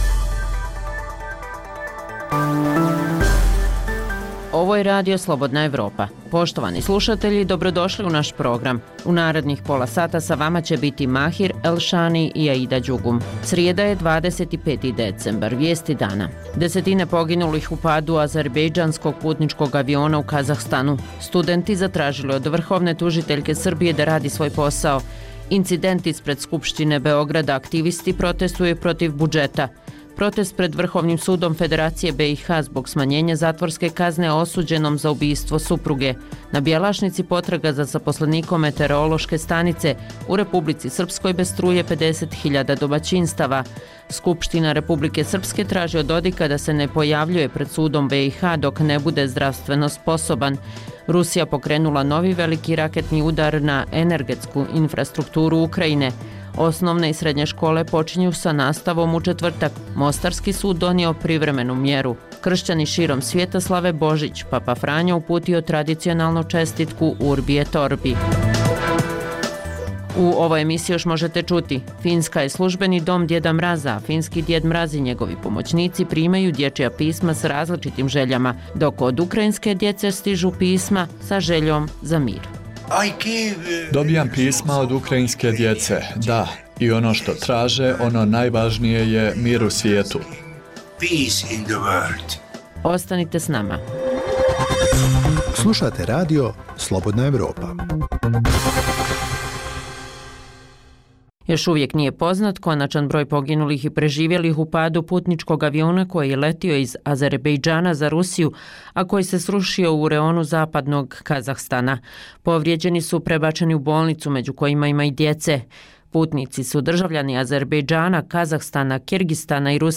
Dnevna informativna emisija sa vijestima, temama, analizama i intervjuima o događajima u Bosni i Hercegovini, regionu i svijetu.